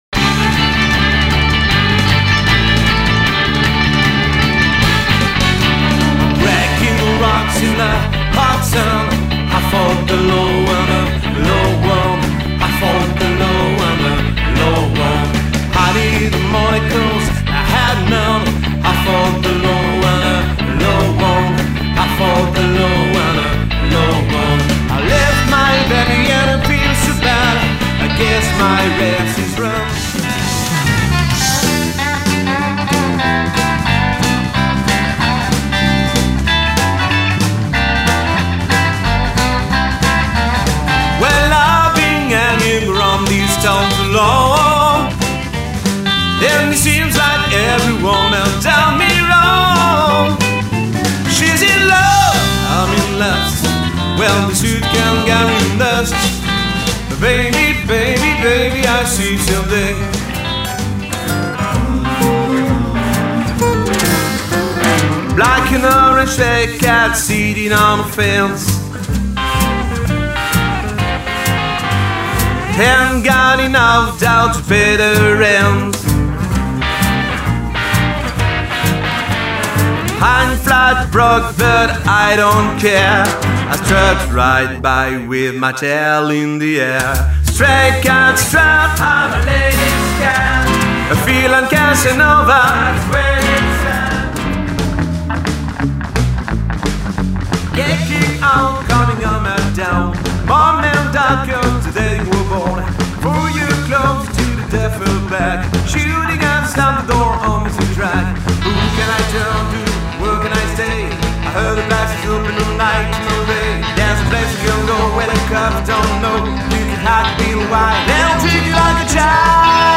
power trio
le Rock’n’Roll, le Rockabilly et la Surf Music.
à la batterie et au chant
à la guitare
à la basse et à la contrebasse